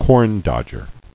[corn·dodg·er]